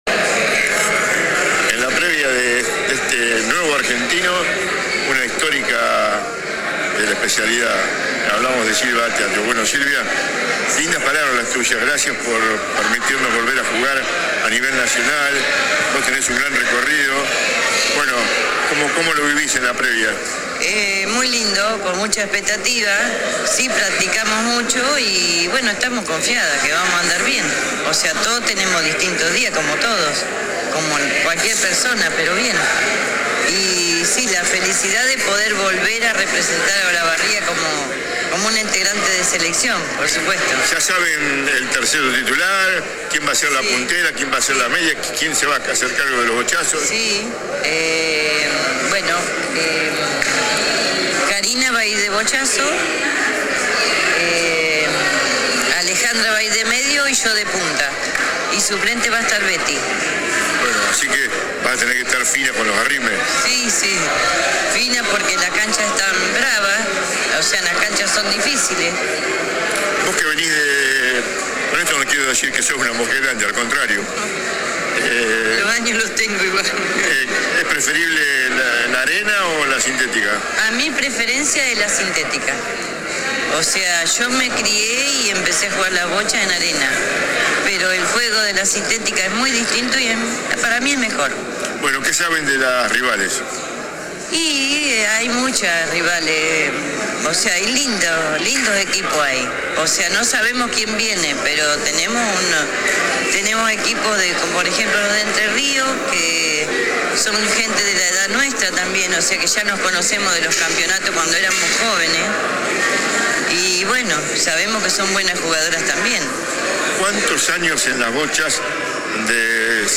En la víspera y en un alto del acto inaugural en Pueblo Nuevo, dialogamos en «Emblema Deportivo» con una histórica de la disciplina.
AUDIO DE LA ENTREVISTA